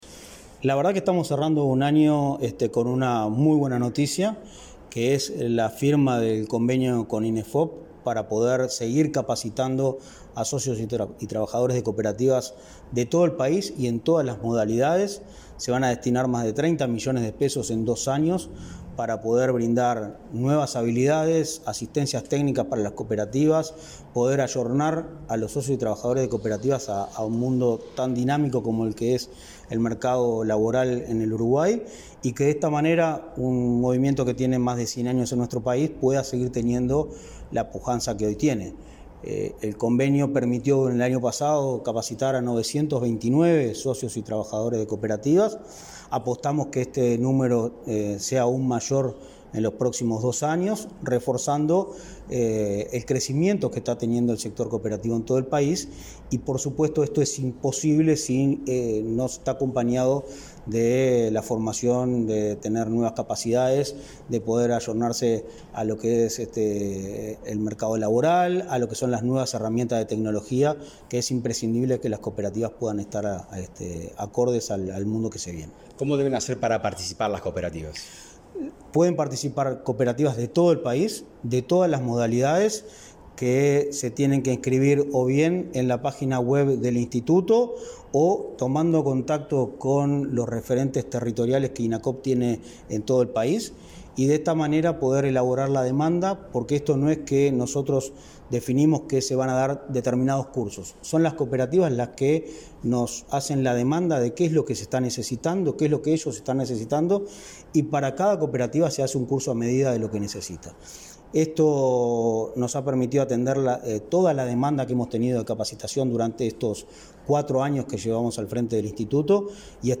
Entrevista al presidente de Inacoop, Martín Fernández | Presidencia Uruguay